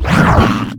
CosmicRageSounds / ogg / general / combat / creatures / alien / he / hurt3.ogg
hurt3.ogg